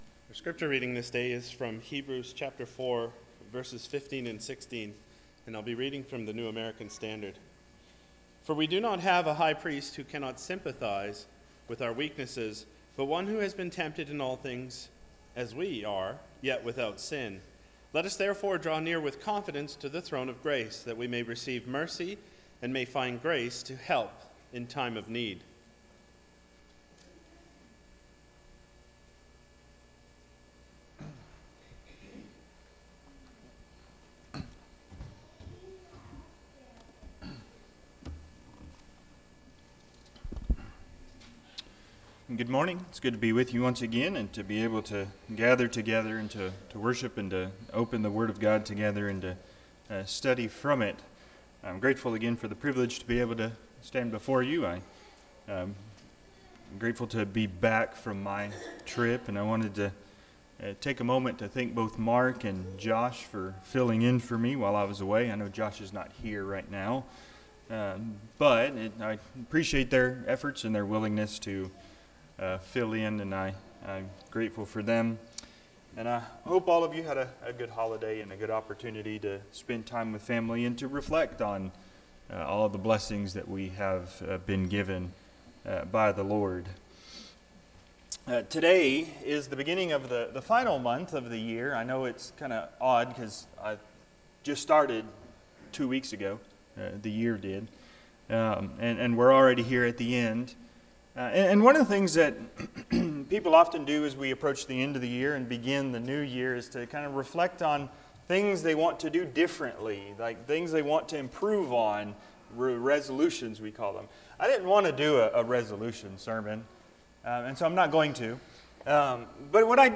Passage: Hebrews 4:15-16 Service Type: Sunday AM